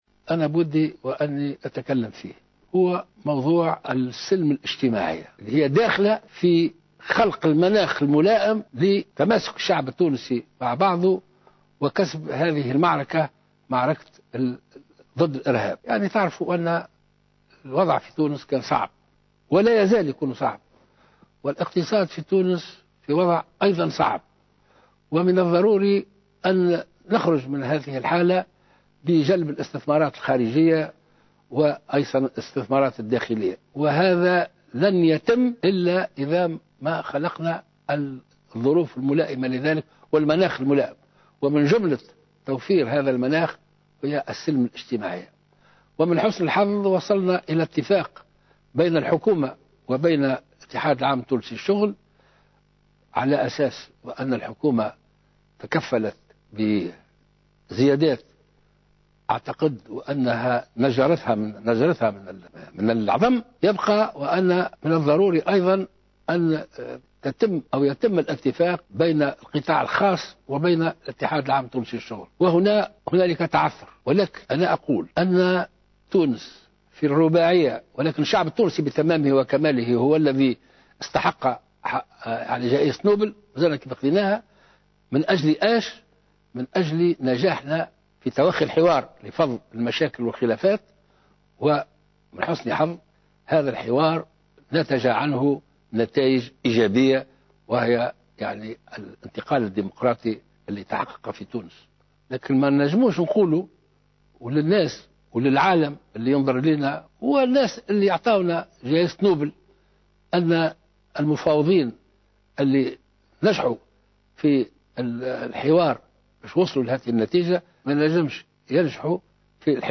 Le président de la République, Béji Caïd Essebsi a abordé deux sujets épineux dans son allocution diffusée dimanche soir sur Al Watania ; la majoration salariale dans le secteur privé et la crise au sein de Nidaa Tounes.